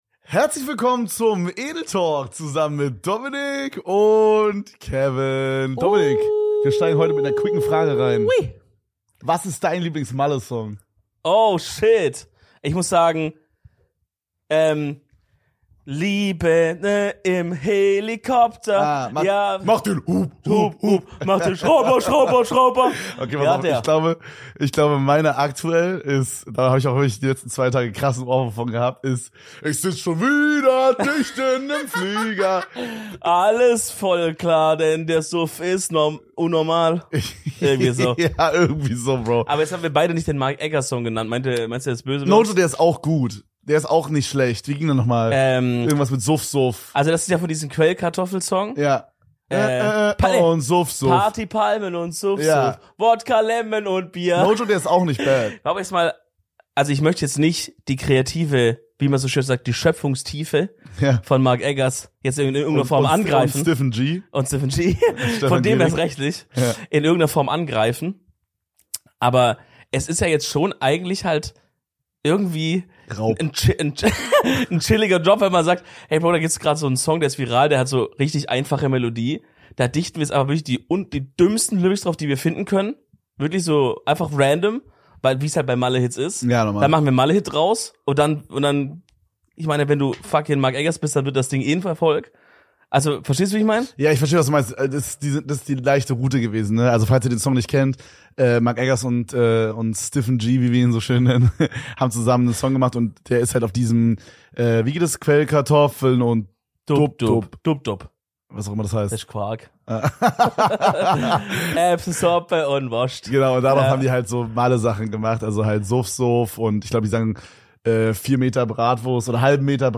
Heute gibts wieder eine gemütliche 2er Folge für euch aus dem Studio, in welcher es jedoch sehr kriminell wird 👀 Hört selbst...